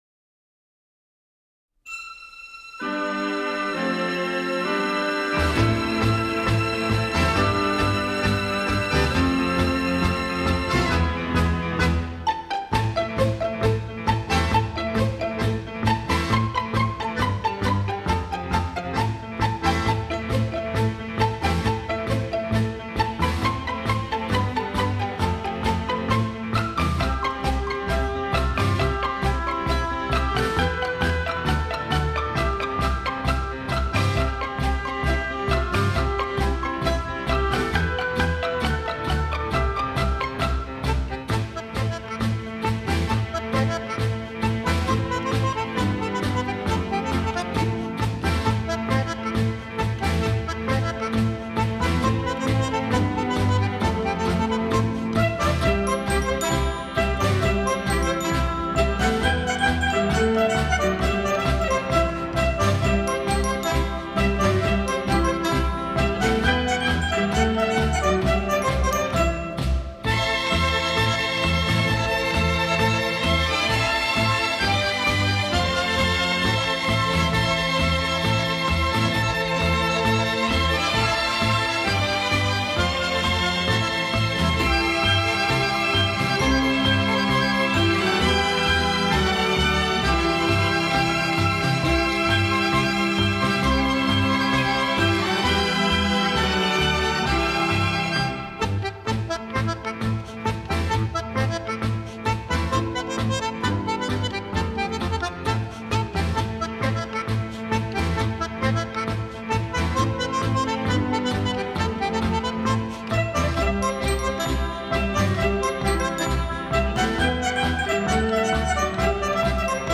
Жанр: Instrumental